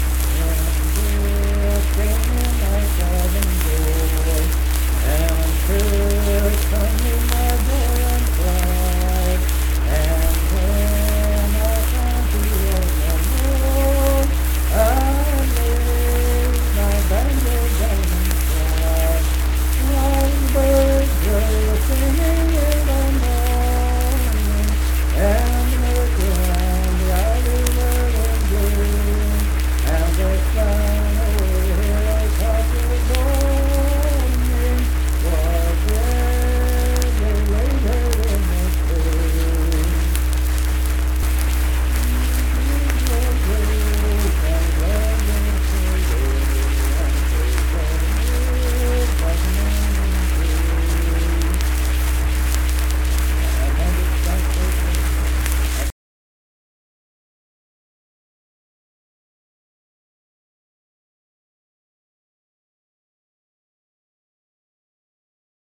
My Wife Susie - West Virginia Folk Music | WVU Libraries
Unaccompanied vocal music
Performed in Kanawha Head, Upshur County, WV.
Voice (sung)